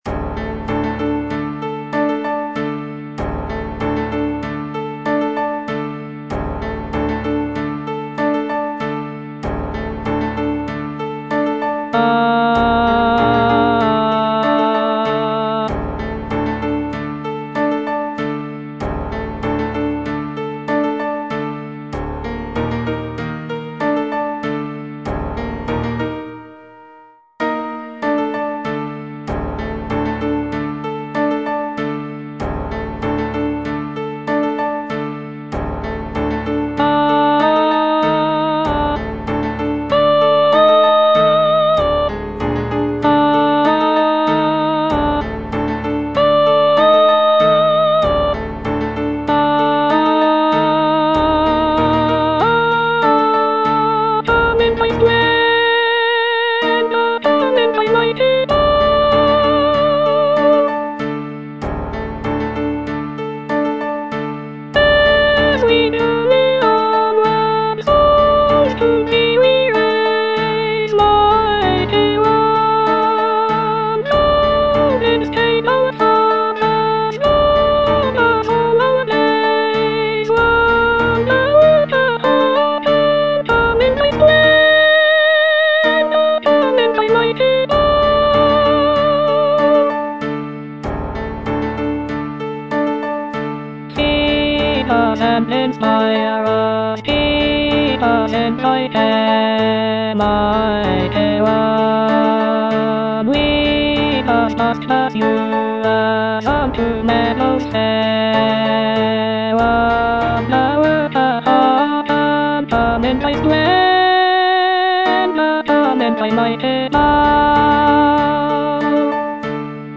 Alto II (Voice with metronome, piano)